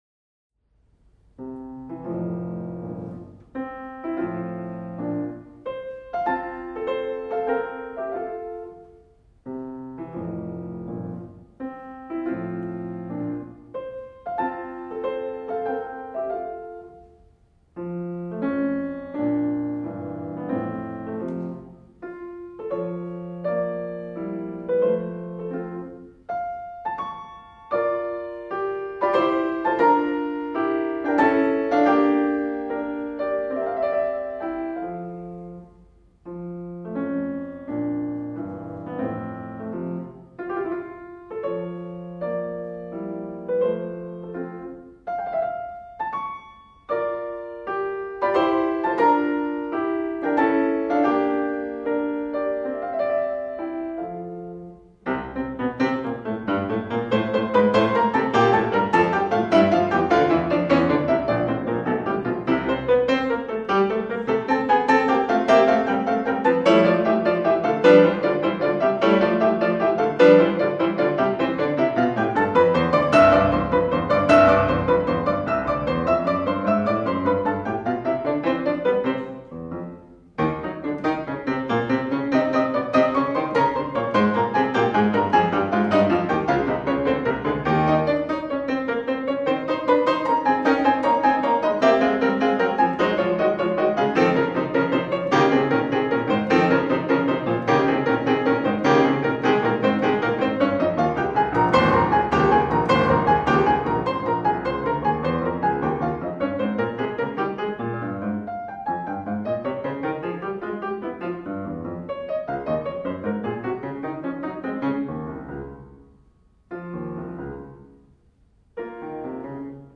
Beethoven-sonata-op-54-movement-I.mp3